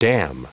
Prononciation du mot dam en anglais (fichier audio)
Prononciation du mot : dam